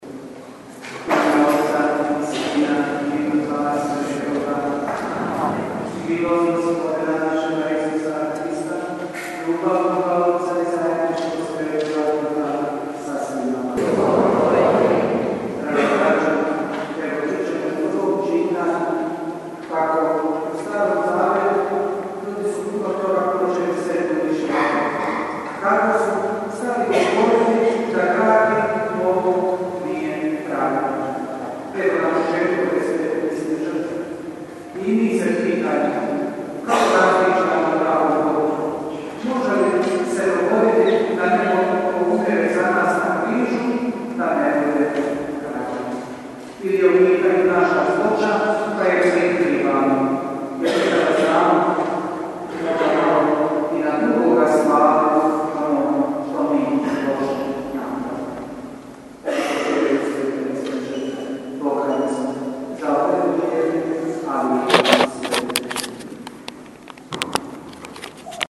UVODNA MISAO